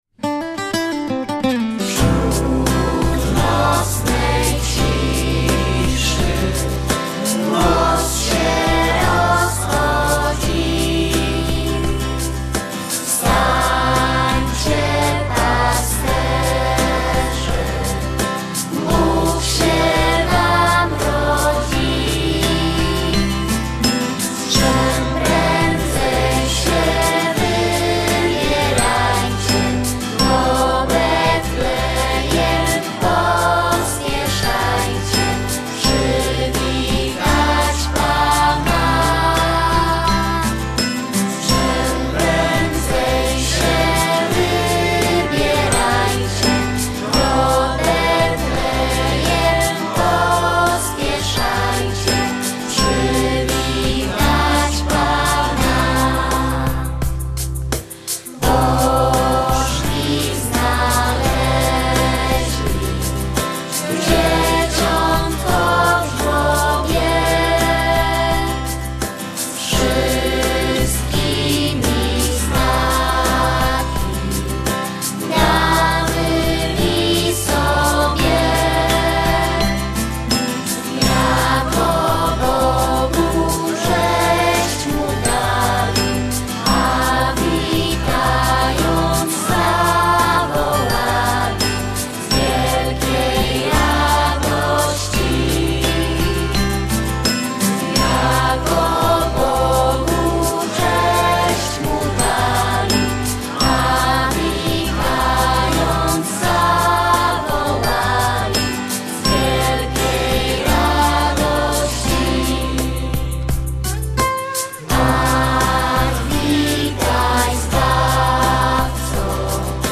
znane kolędy
śpiew
gitary, samplery
instrumenty perkusyjne
kontrabas
gitara basowa
perkusjonalia, klawisze